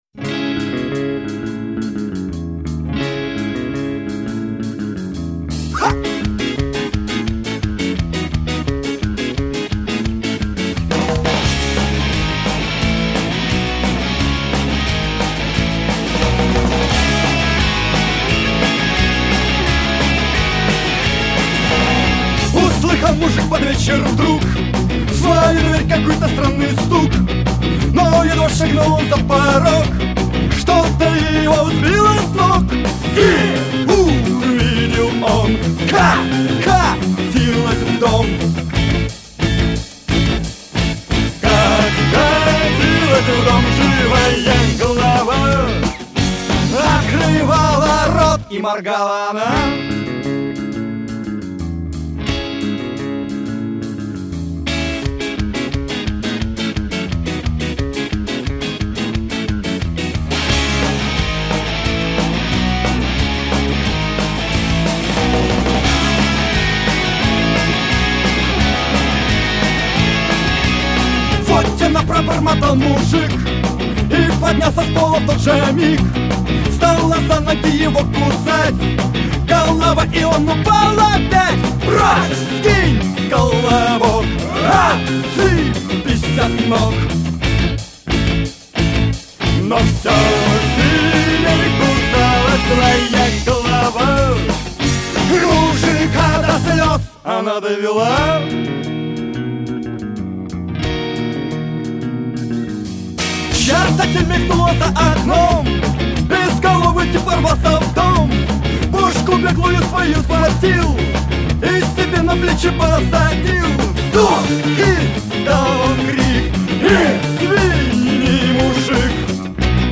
Рок [115]